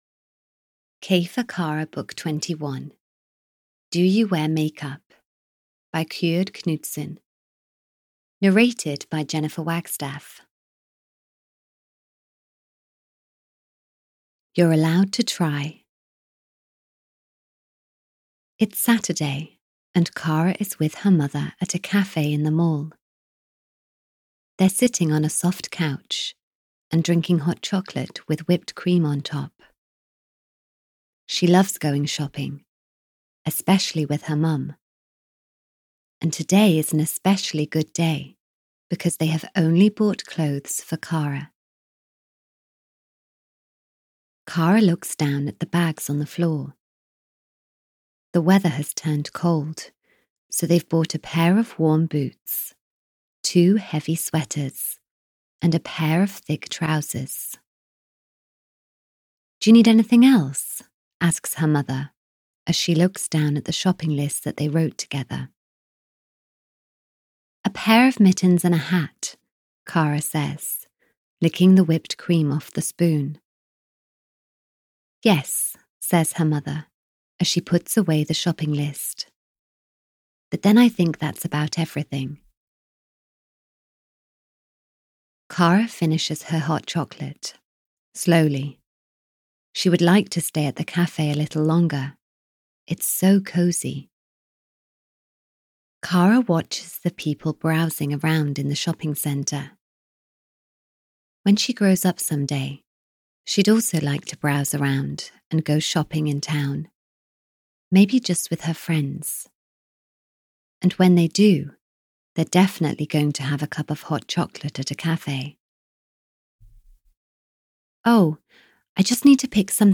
Audio knihaK for Kara 21 - Do You Wear Makeup? (EN)
Ukázka z knihy